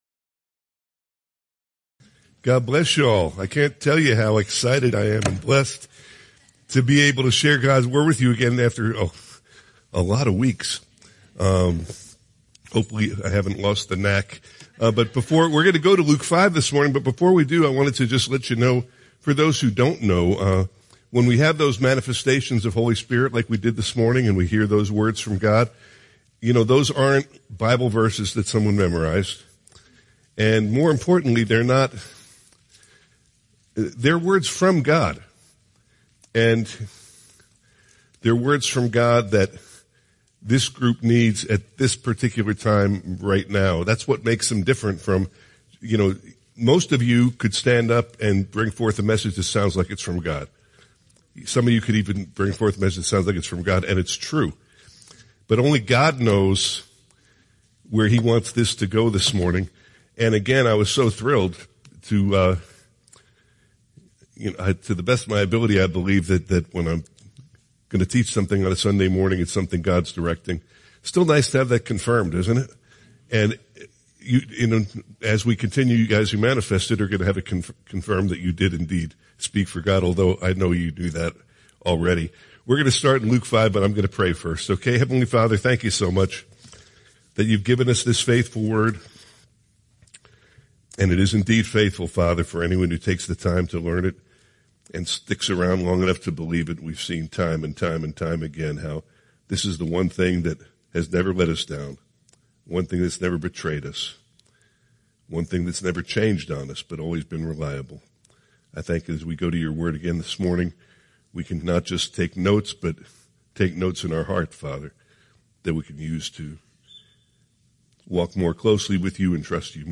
Teachings | Teachings